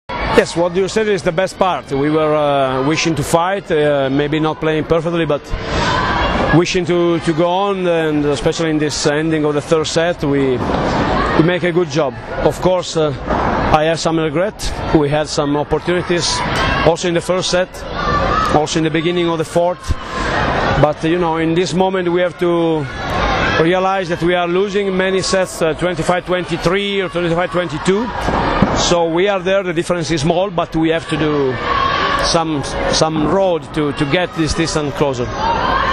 IZJAVA MAURA BERUTA